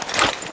Index of /server/sound/weapons/tfa_cso/bazooka
draw.wav